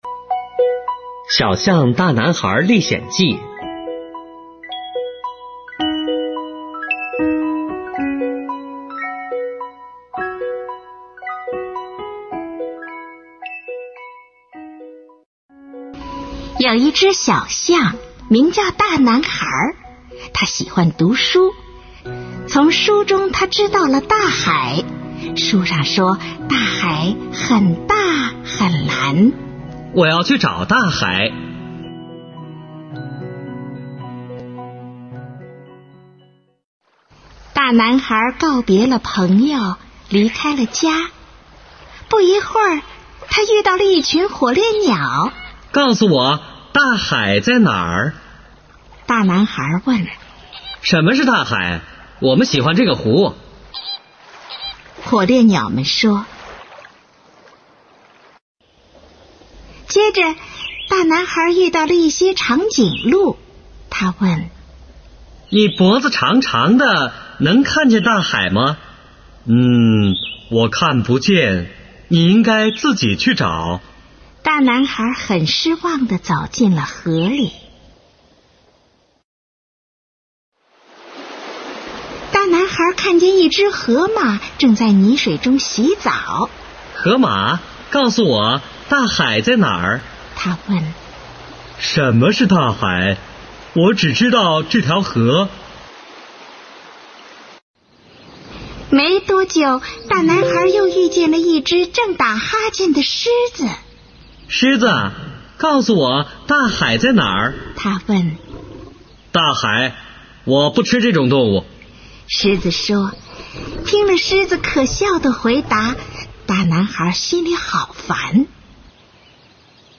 绘本故事 | 《小象大男孩历险记》